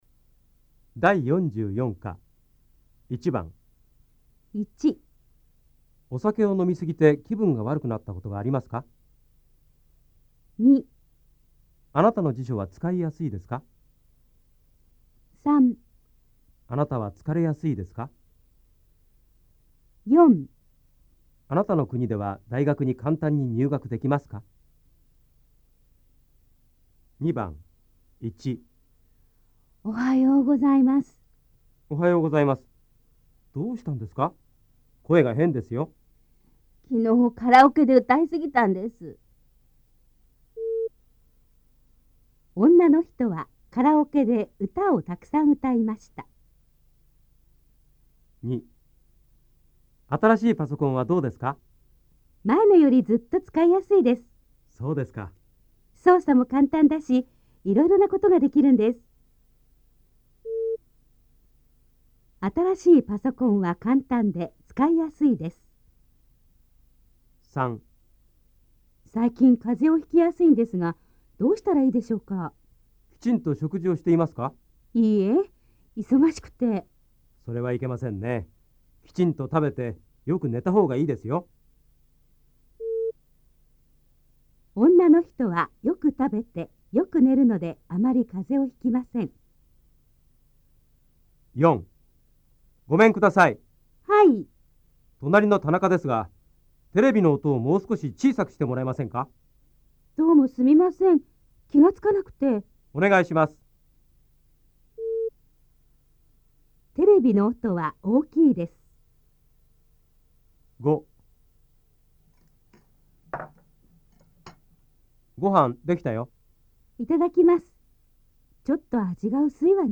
大家的日语-第44课听力练习